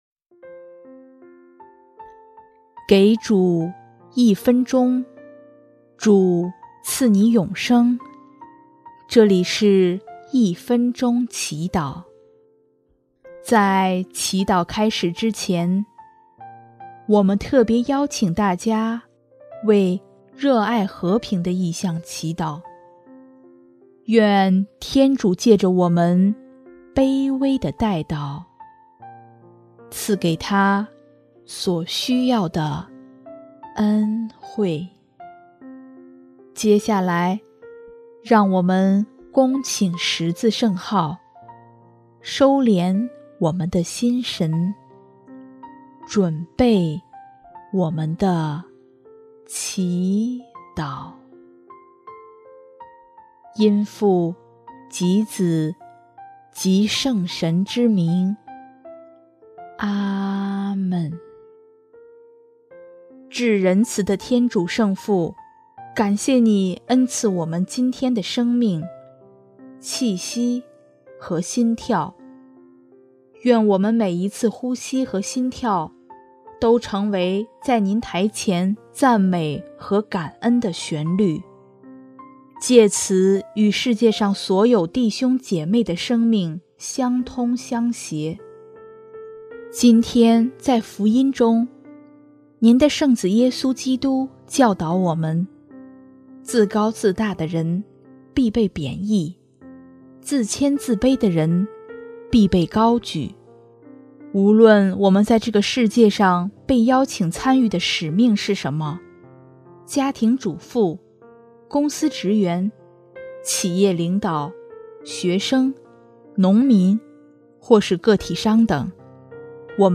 【一分钟祈祷】|11月5日 一呼一吸，皆成为父前的赞美